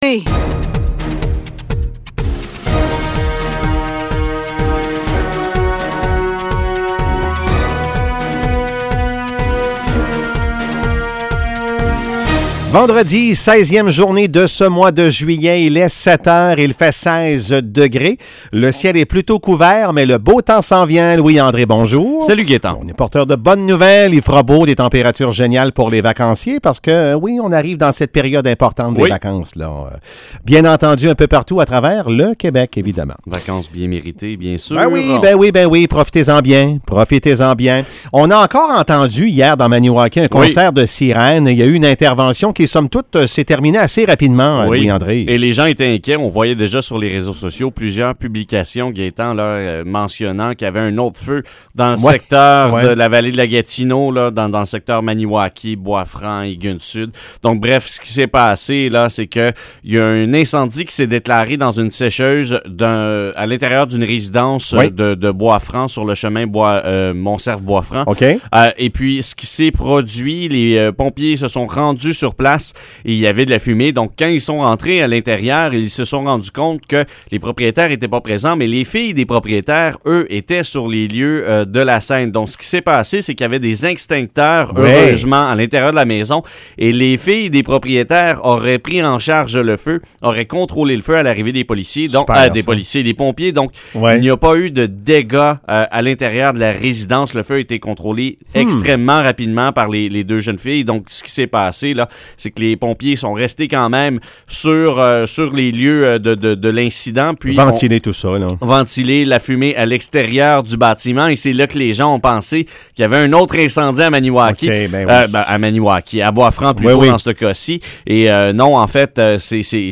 Nouvelles locales - 16 juillet 2021 - 7 h